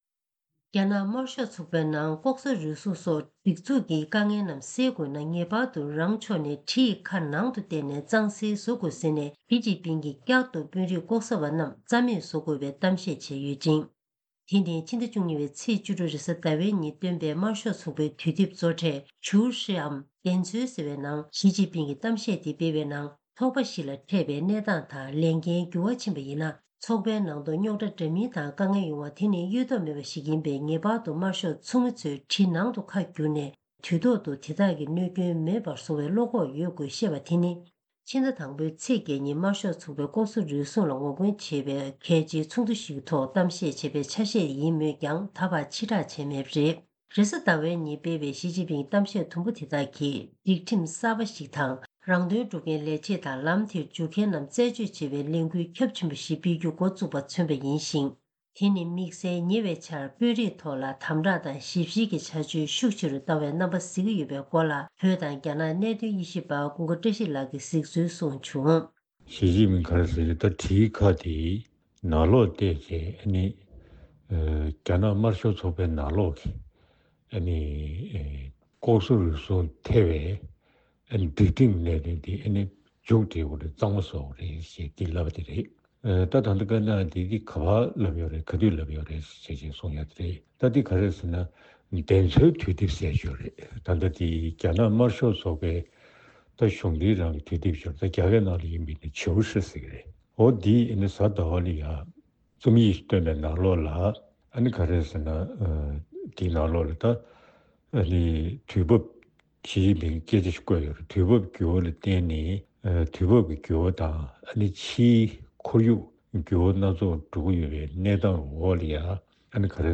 གསར་འགྱུར་དཔྱད་གཏམ